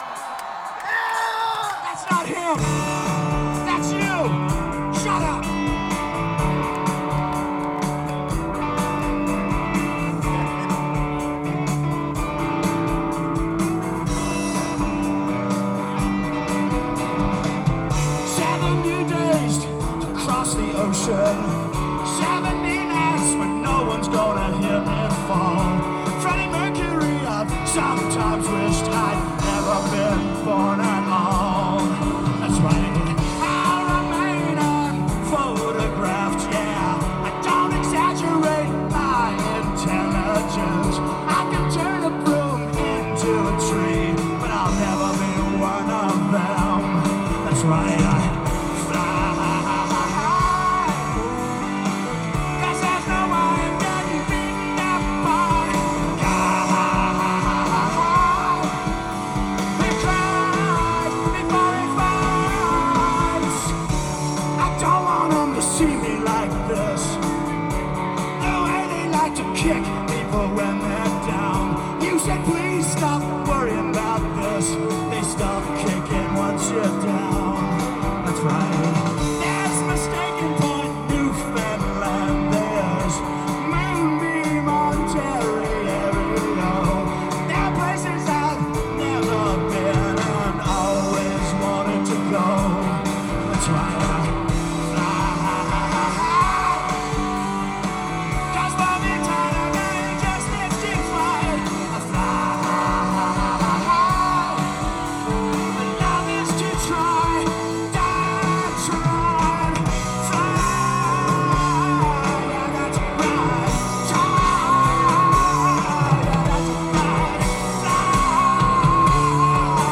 Source: SBD
(2nd time played live)